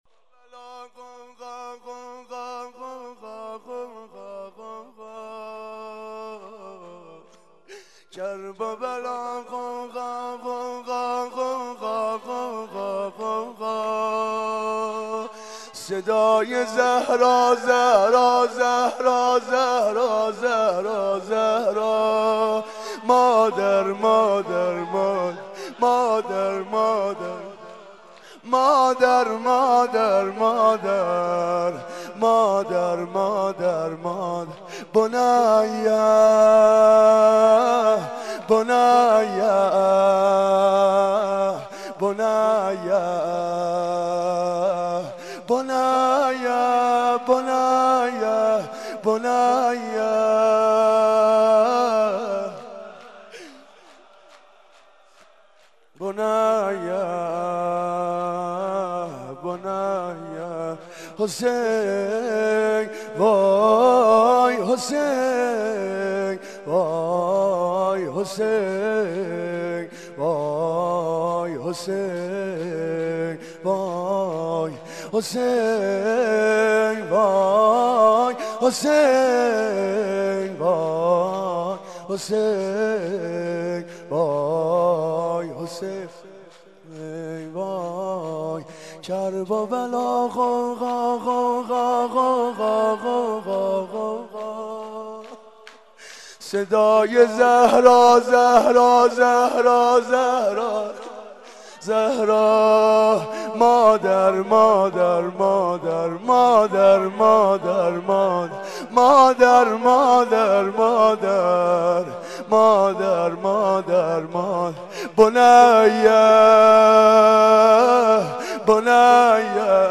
مناسبت : شب دوم محرم